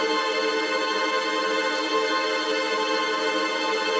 GS_TremString-Adim.wav